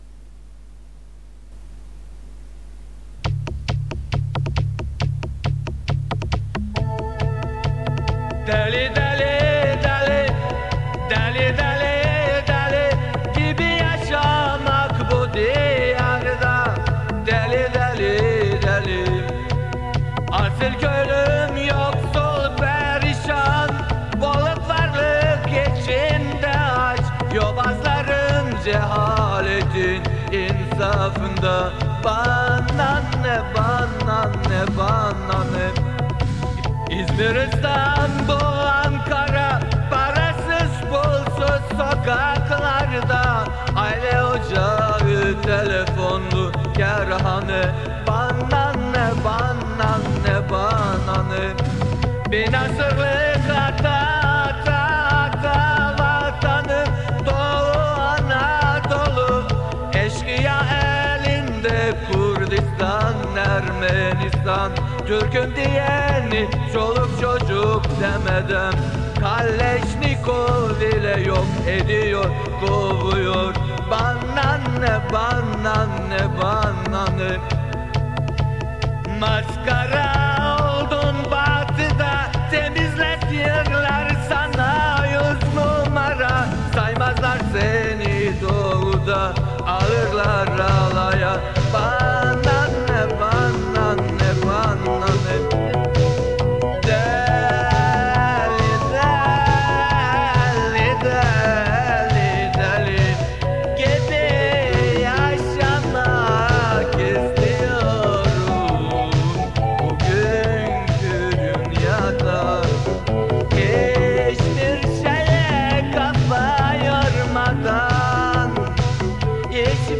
Ticaretten, paradan hiç bahsetmeyen, kötü kayıtlı şarkılar yapan bir üniversite hocasının şarkısı: